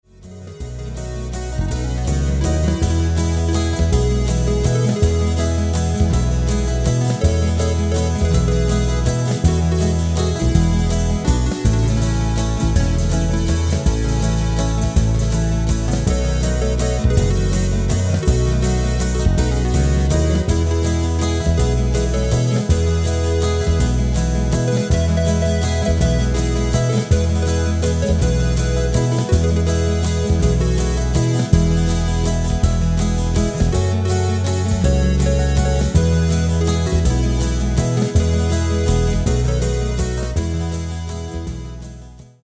Rubrika: Folk, Country
Karaoke